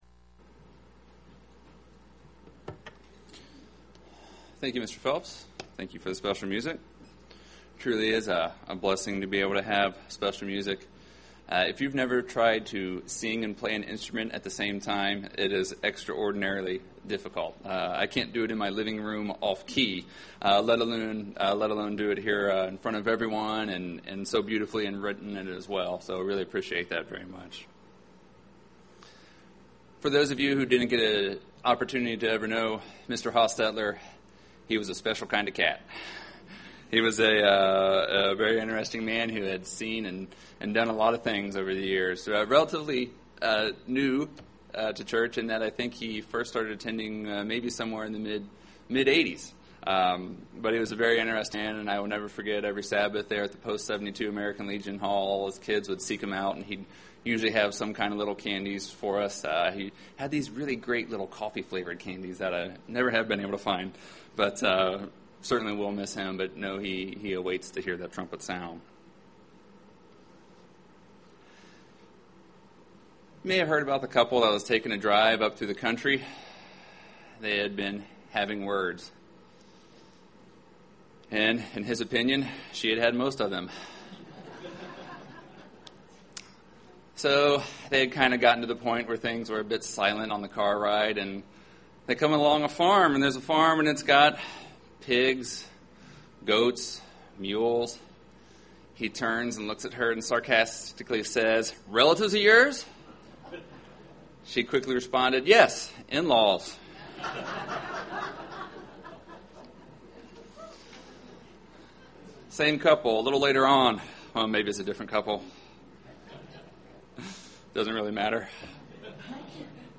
Given in Cincinnati East, OH
UCG Sermon relationship Studying the bible?